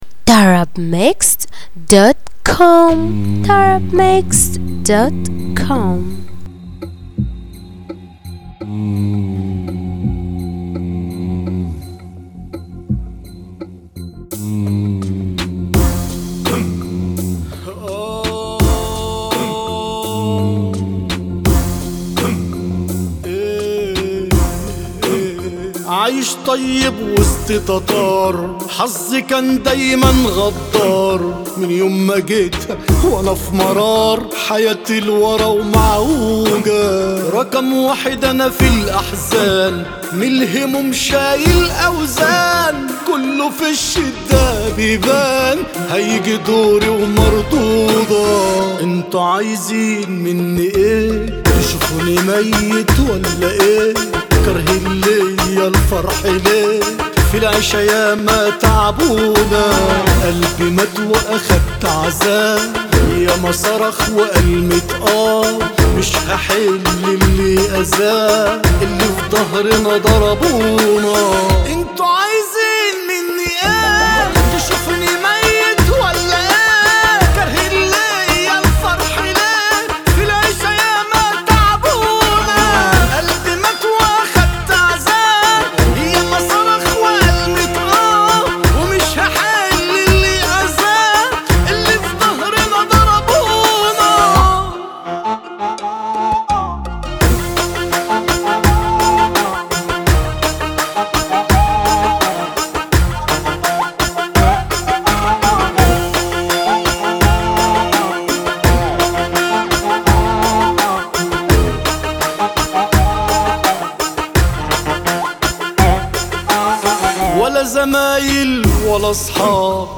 • النوع : shobeiat